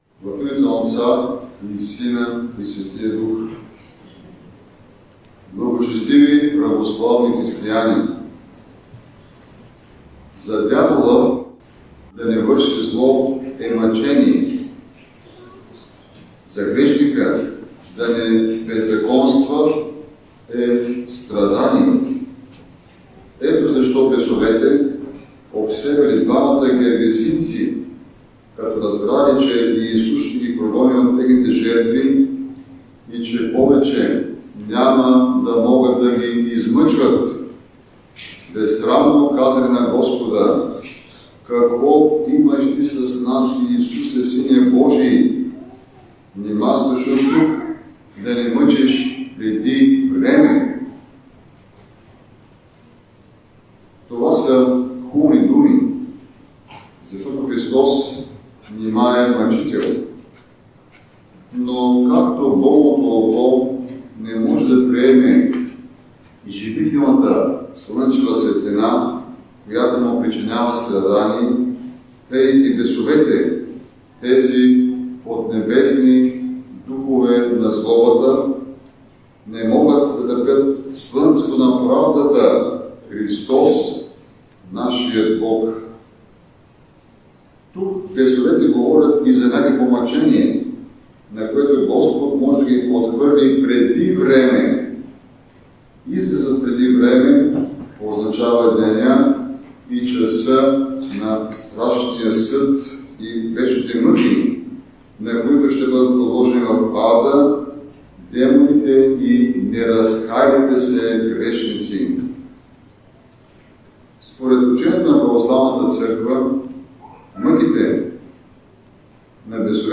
Водещи новини Неделни проповеди